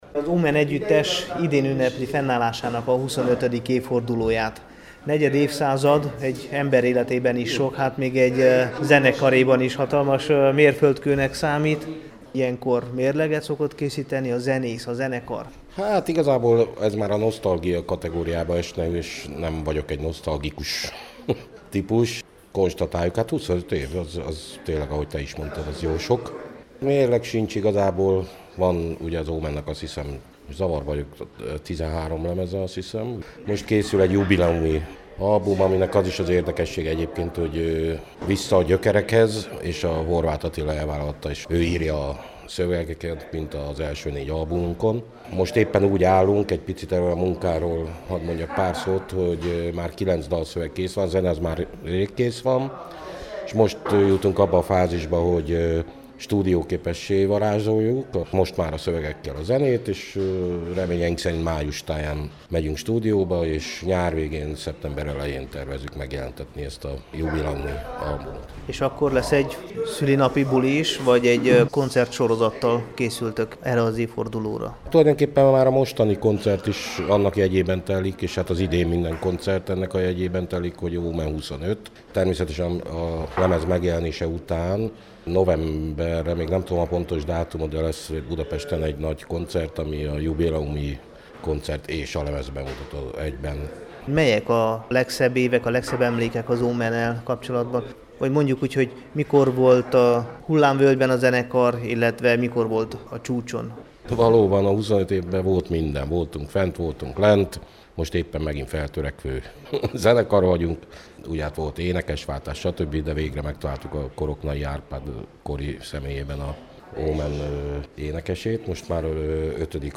Hallgassa meg a Temesvári Rádió szerdai ifjúsági műsorában elhangzó interjúkat